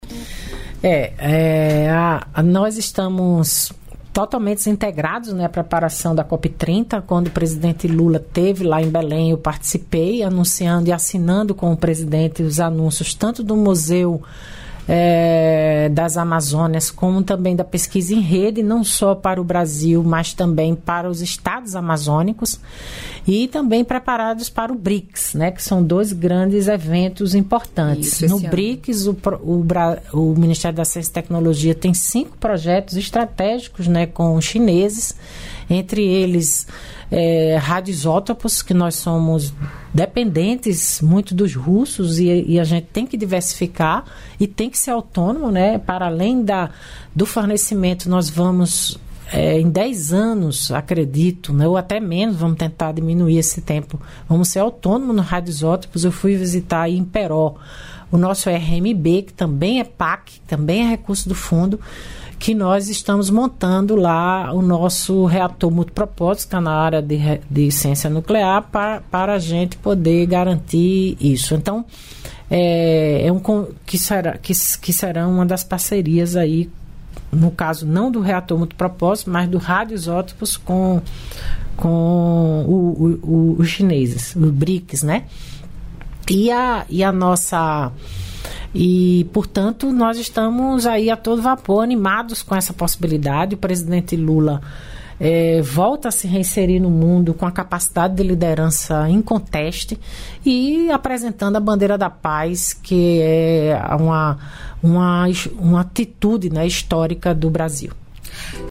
Trecho da entrevista concedida pela ministra das Mulheres, Cida Gonçalves, nesta quinta-feira (22), para emissoras de rádio de todo o país, direto dos estúdios da EBC, em Brasília.